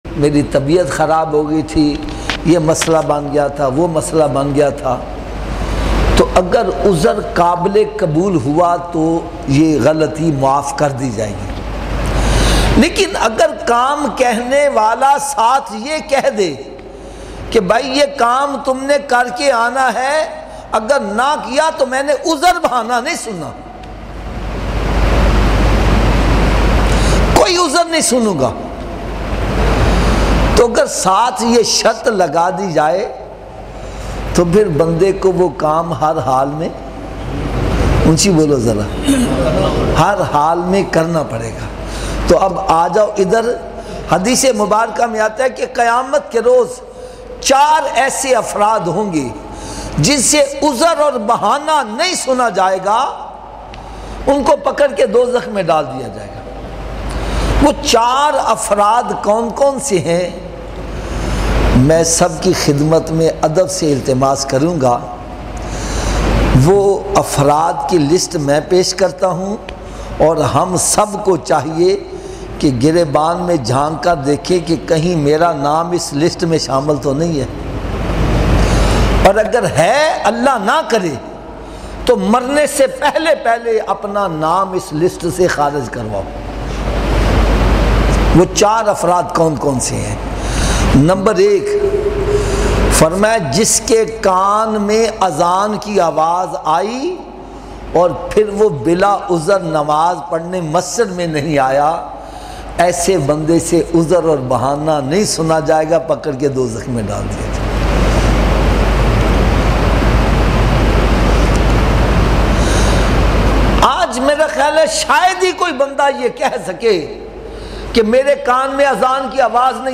Emotional Full Bayan